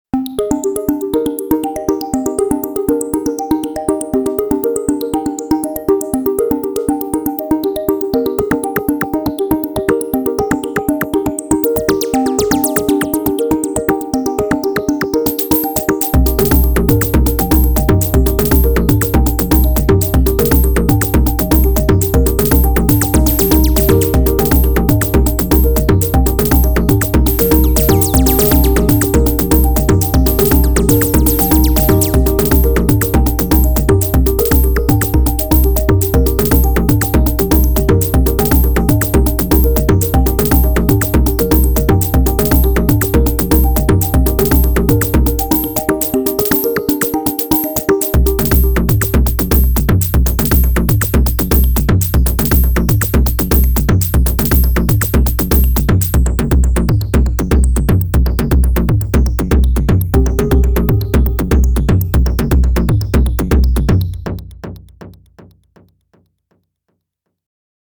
Here’s a sub-zero clicky dub thing.
I guess this ended up in the latter category :sweat_smile: The recording sounds quite low since the bass stole most of the energy.